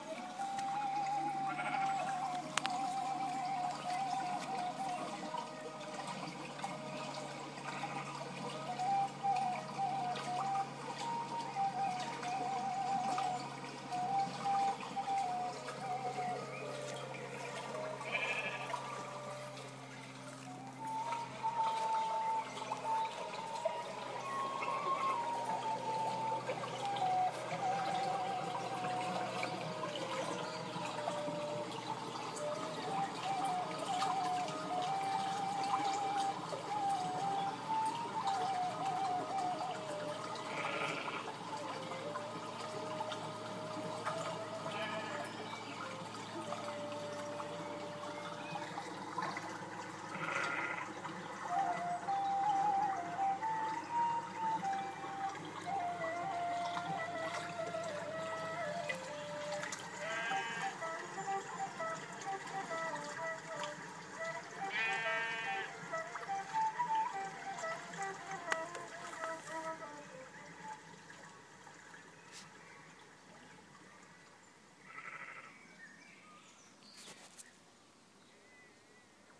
Resembling a boom microphone of extraordinary proportions, the work plays a selection of tracks designed to heighten the ambience of the Arboretum: from the 1942 BBC recording of nightingales, which inadvertently picked up the sound of Allied bombers on their way to Mannheim, to the laughter and clinking glasses of a garden party.